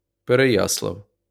uttal(fil); ryska Переяслав, Perejaslav) är en stad i Kiev oblast i Ukraina Den är administrativ huvudort för Perejaslav-Chmelnytskyj rajon och folkmängden uppgick till 27 923 invånare i början av 2012.[1] Staden ligger vid floden Trubizj, som är en biflod till Dnepr.